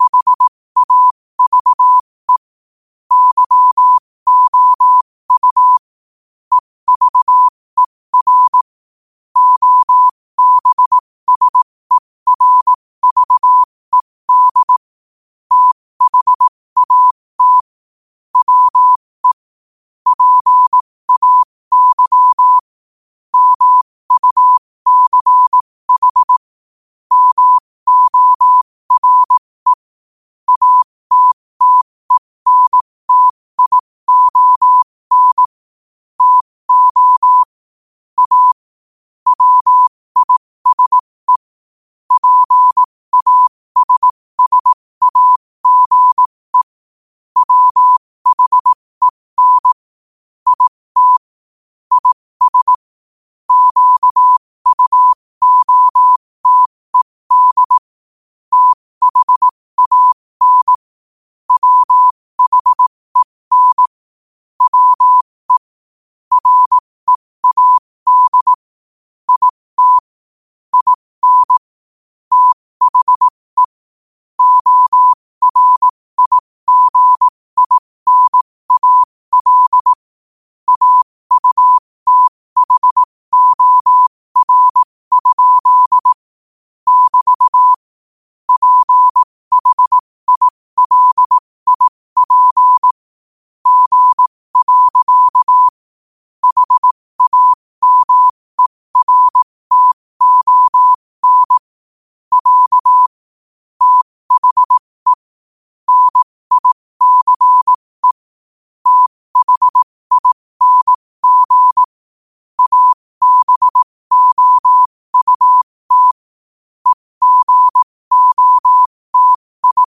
New quotes every day in morse code at 15 Words per minute.